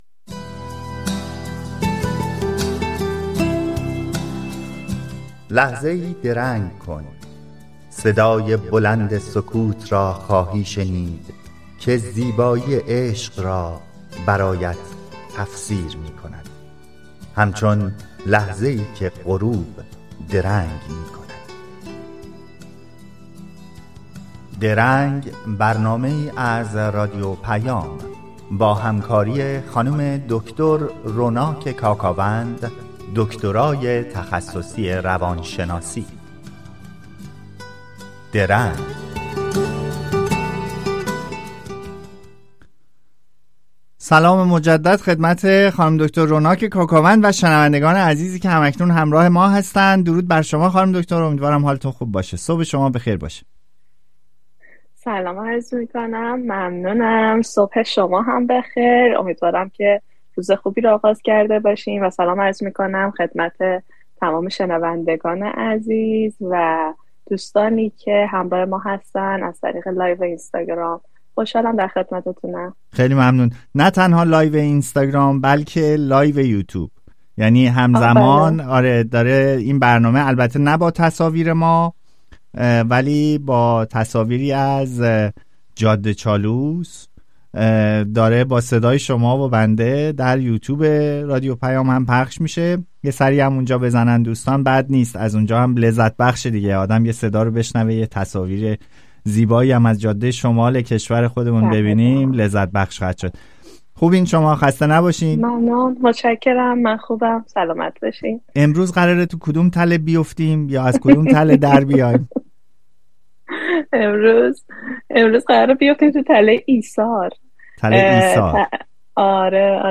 شما در این صفحه می‌توانید به برنامهٔ «درنگ» که هر هفته به‌صورت زنده از رادیو پیام گوتنبرگ سوئد پخش می‌شود، گوش دهید. این برنامه با هدف پرداختن به موضوعات متنوع اجتماعی، روانشناختی و فرهنگی تهیه و ارائه می‌شود.